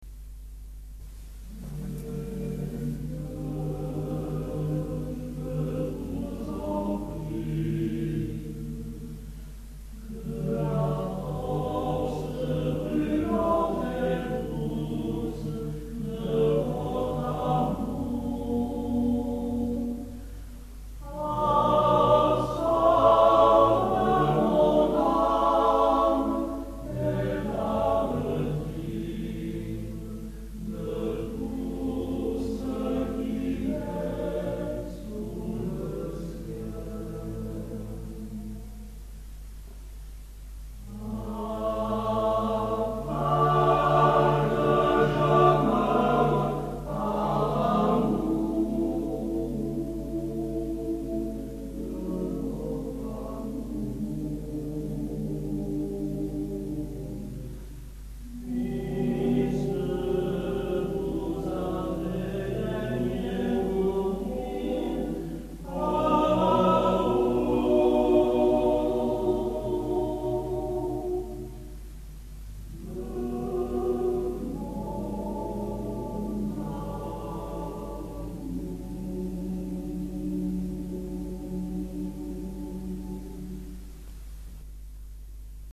Concert  Samedi 30 aout 1986 Eglise de LAVAUDIEU
Saltarelle & Valets de Choeur
Extrait du concert de LAVAUDIEU